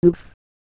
ooff1.wav